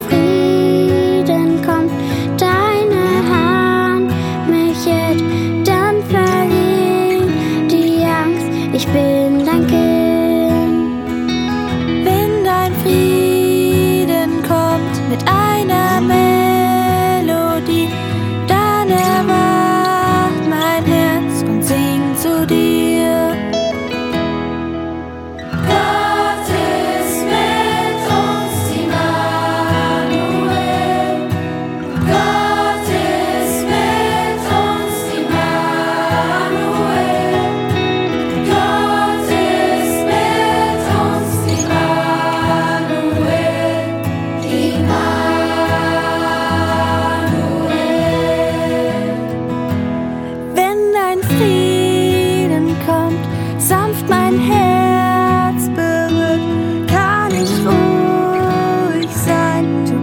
Kinderchor
moderne Kinderlieder • tanzbare Musik